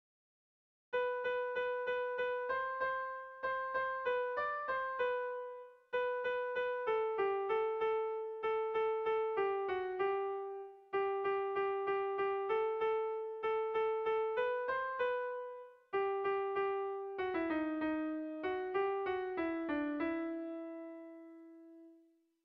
Sentimenduzkoa
ABDE